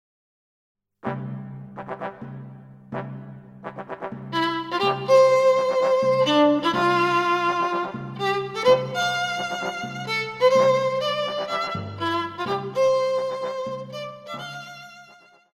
小提琴
樂團
演奏曲
世界音樂,融合
僅伴奏
沒有主奏
沒有節拍器
曲子附有演奏版和不含主旋律的伴奏版。